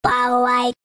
Play More Beep - SoundBoardGuy
Play, download and share More beep original sound button!!!!
more-beep.mp3